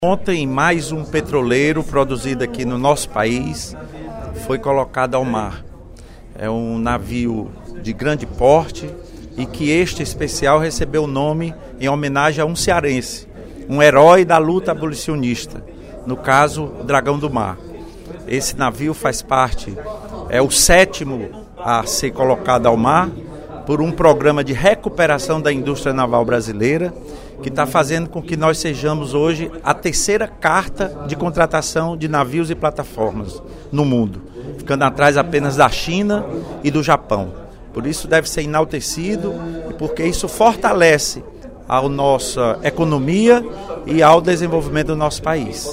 No primeiro expediente da sessão plenária desta terça-feira (15/04), o deputado Lula Morais (PCdoB) destacou a solenidade de entrega do petroleiro Dragão do Mar, da Transpetro, subsidiária da Petrobras, construído pelo Estaleiro Atlântico Sul (EAS), em Pernambuco, na segunda-feira (14/04).